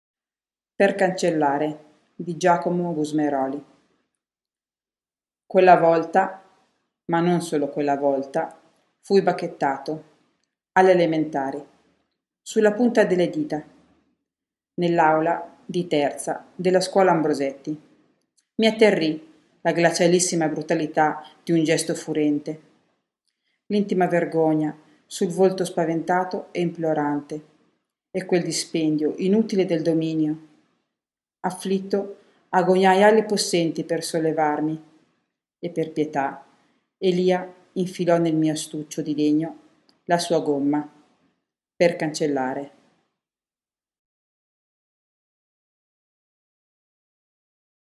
Una versione audio possono essere trovate qui sotto con i link ad altre informazioni sul poeta: